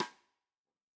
Index of /musicradar/Snares/Tama Wood